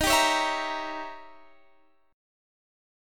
Listen to Eb7b9 strummed